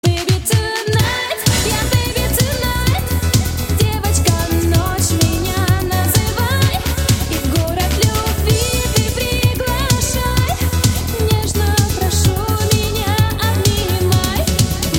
• Качество: 128, Stereo
dance
disco
ретро